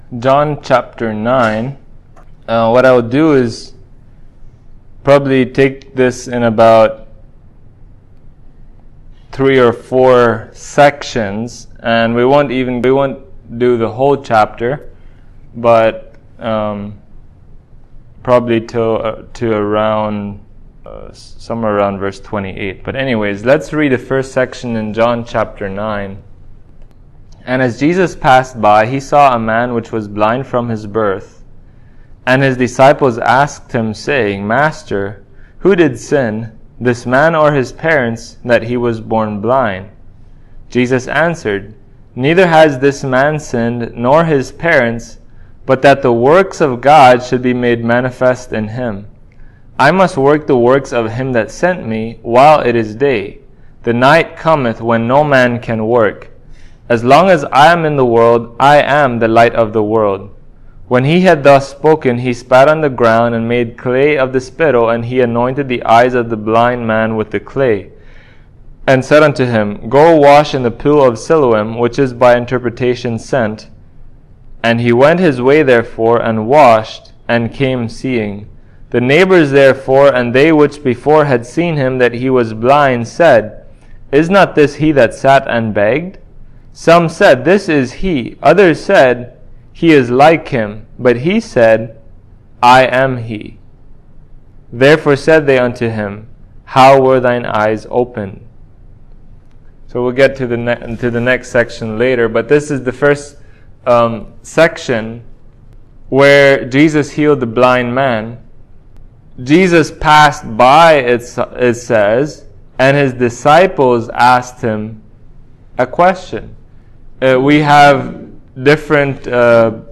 2022 The Works of God for the Glory of God Preacher